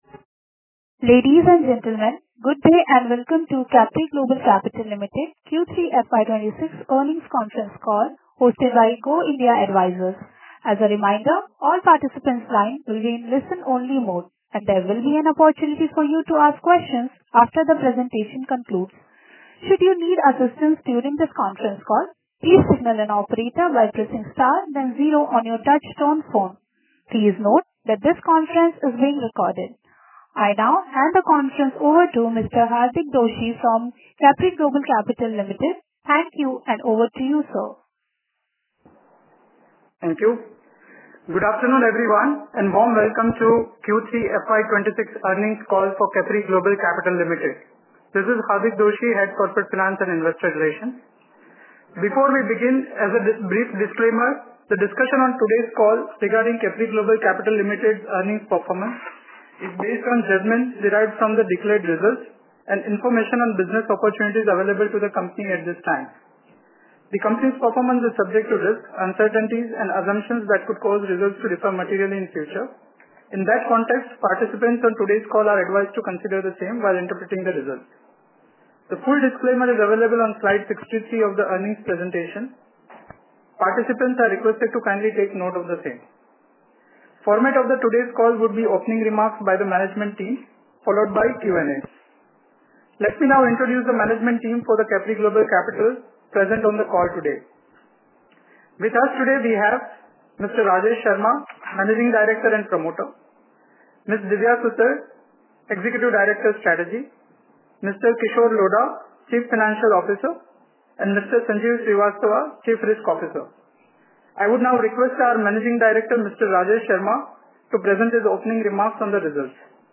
CGCL Q3FY26 Earnings Call Transcript Audio.mp3